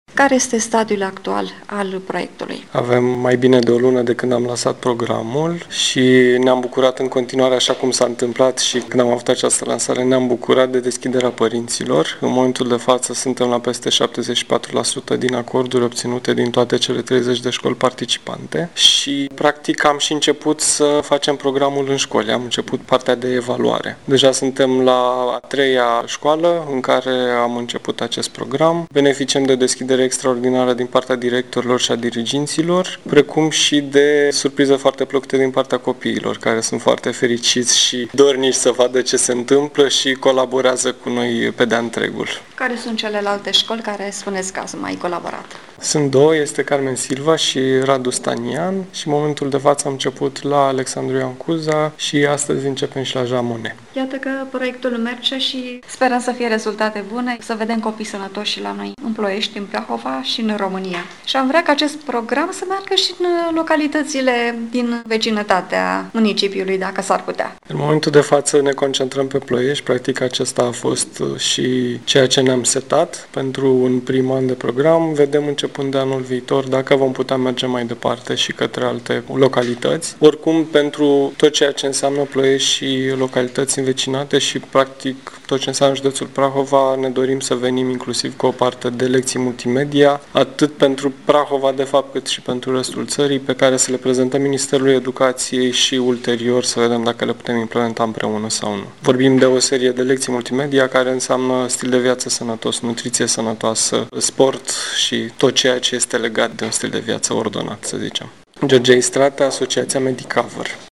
Reportaje și interviuri radio difuzate la Radio SOS Prahova, în data de 14 noiembrie 2017, cu ocazia Zilei Mondiale a Diabetului.
Interviu